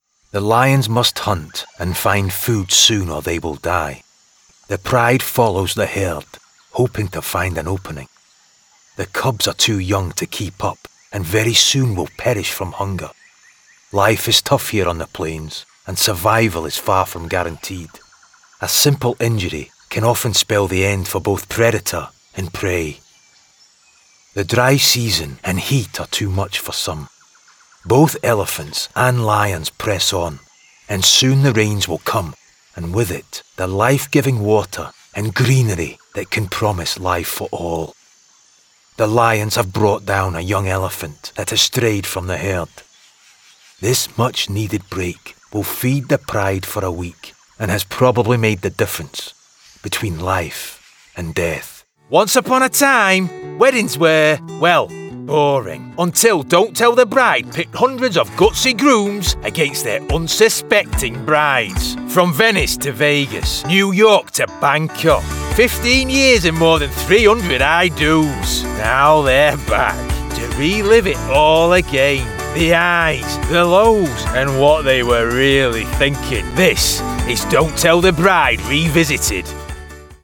Narration Reel
• Native Accent: Glasgow, Yorkshire
Powerful and gritty